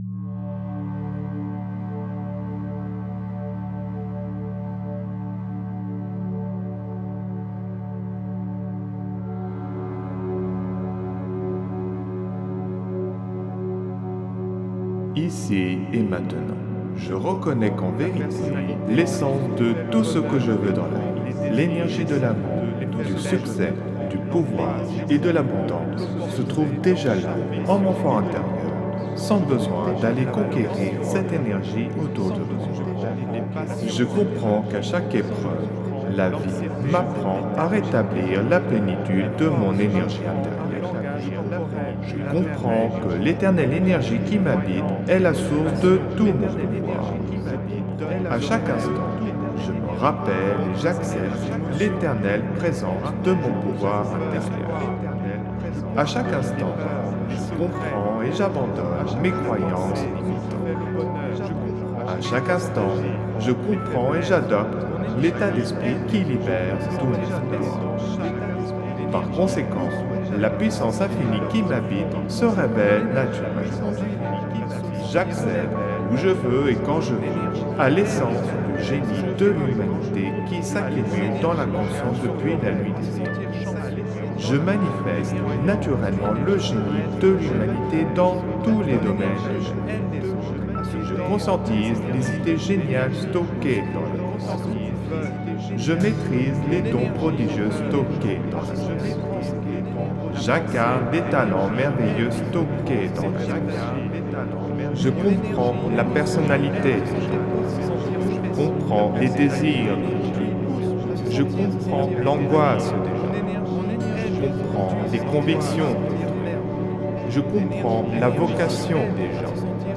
(Version ÉCHO-GUIDÉE)
Alliage ingénieux de sons et fréquences curatives, très bénéfiques pour le cerveau.
Pures ondes gamma intenses 75,00 Hz de qualité supérieure. Puissant effet 3D subliminal écho-guidé.